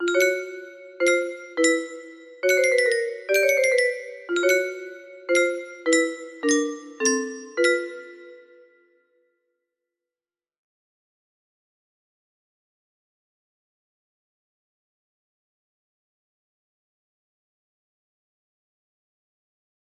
Frore Tedium music box melody